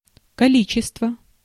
Ääntäminen
Tuntematon aksentti: IPA: /kɑ̃.ti.te/